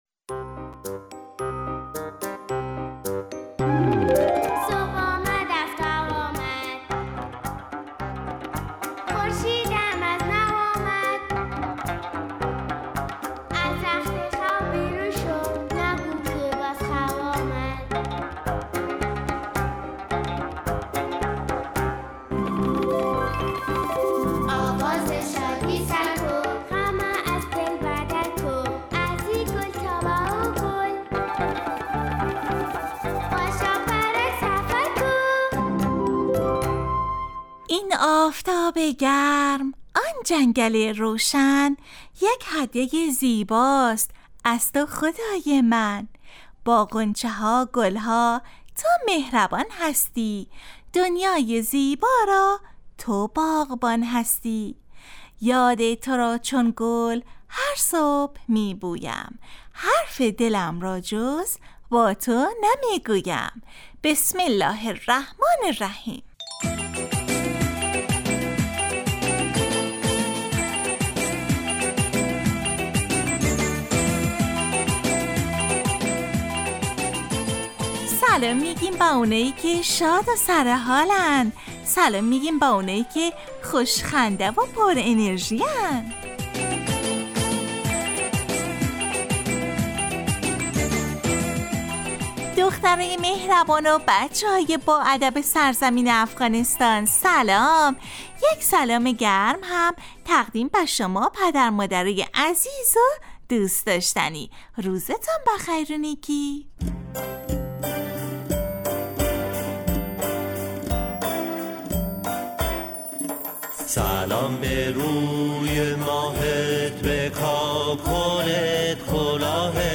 برنامه ای ترکیبی نمایشی است که برای کودکان تهیه و آماده میشود.این برنامه هرروز به مدت 15 دقیقه با یک موضوع مناسب کودکان در ساعت 8:15 صبح به وقت افغانستان از رادیو دری پخش می گردد.